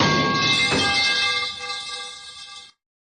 sfx_bump.ogg